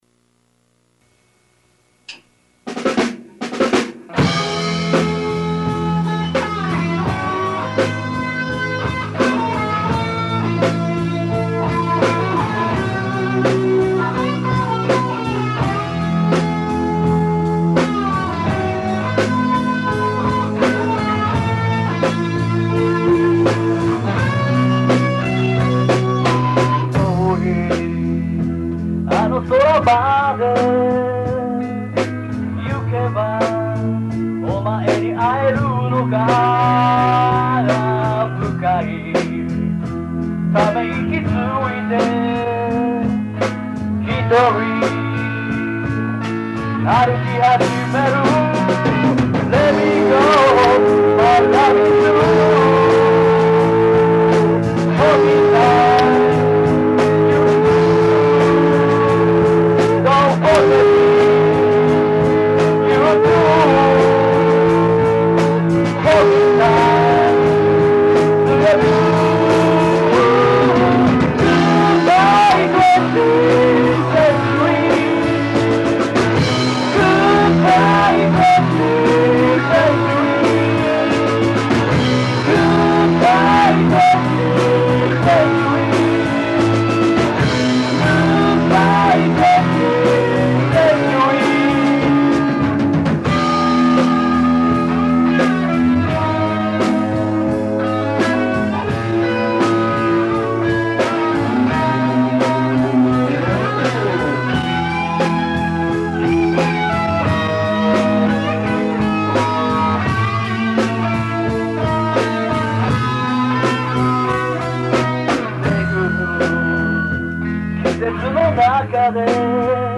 50年〜70年代のロック・オールディーズを、中心に練習に励んでいるおじさん・おばさんバンドです！
Key.＆Vo.
Bass.
Gt.＆Vo.